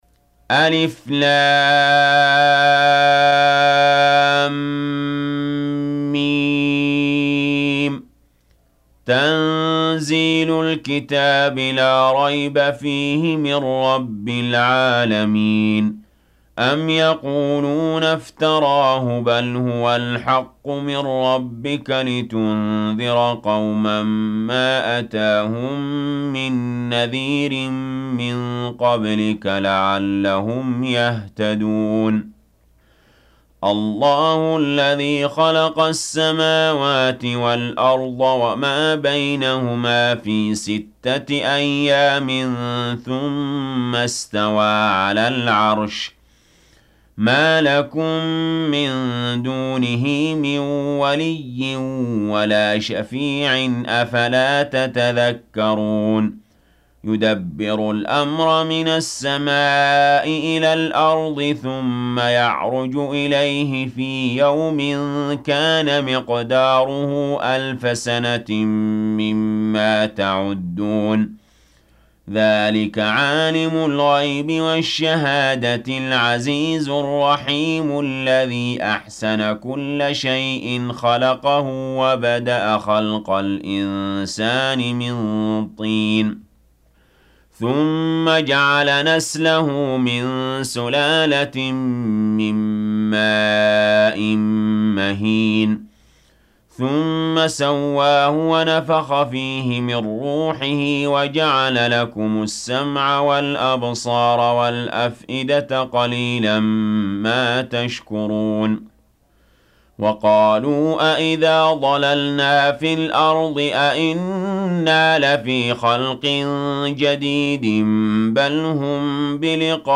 32. Surah As�Sajdah سورة السجدة Audio Quran Tarteel Recitation
Surah Sequence تتابع السورة Download Surah حمّل السورة Reciting Murattalah Audio for 32.